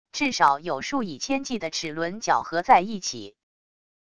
至少有数以千计的齿轮绞合在一起wav音频